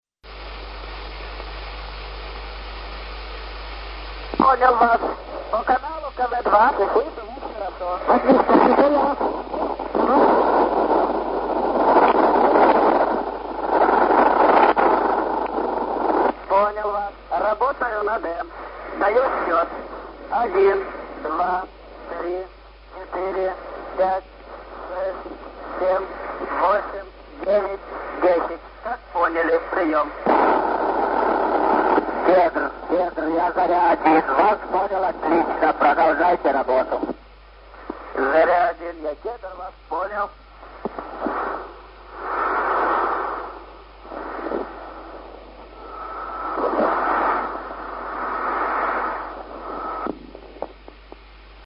Ю.А. Гагарин. Запись переговоров между космическим кораблем Восток-1 и Центром управления полетом.